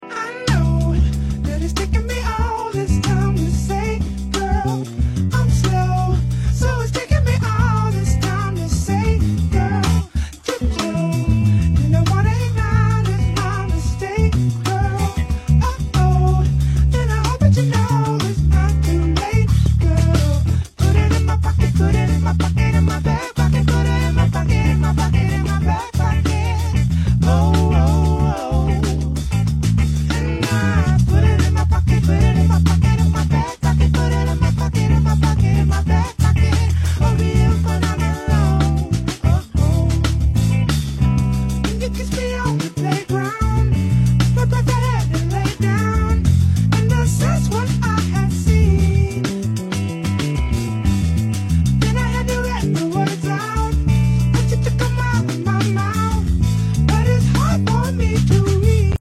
on the keys jamming
virtual Bass